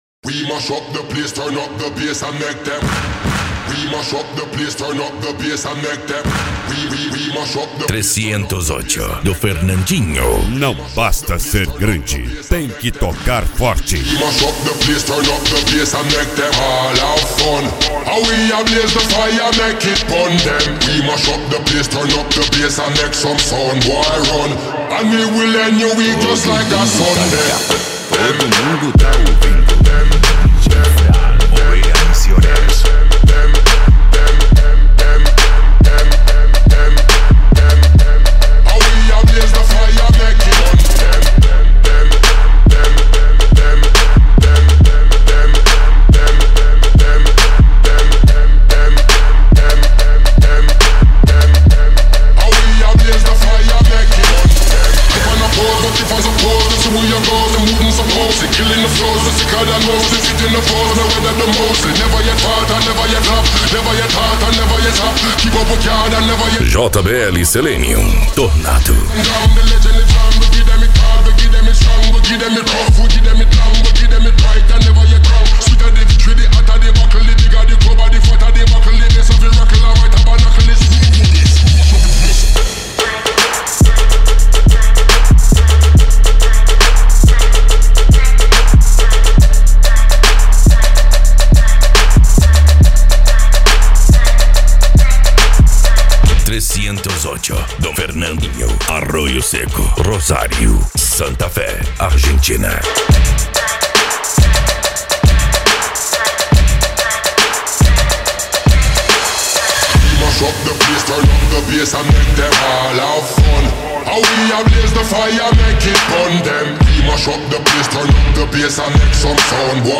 Bass
Cumbia
Funk
PANCADÃO
Psy Trance
Remix